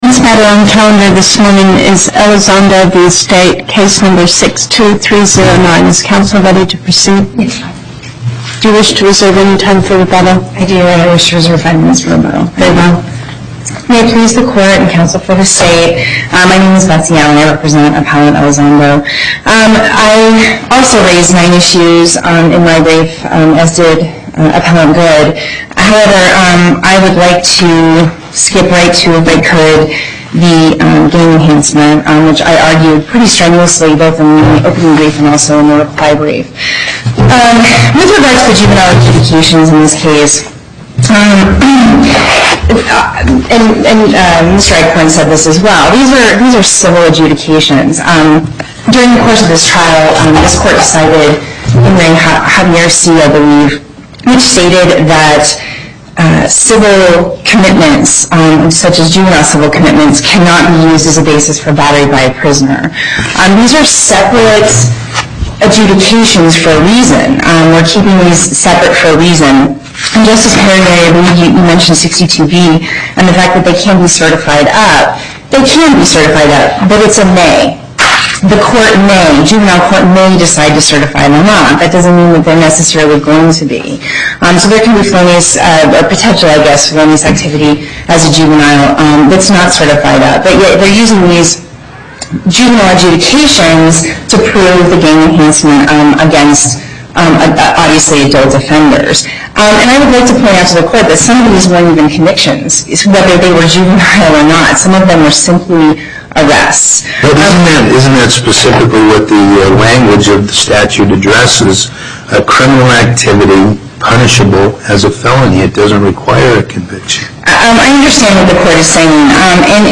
Location: Las Vegas Before the Northern Nevada Panel, Justice Pickering Presiding
as Counsel for the Appellant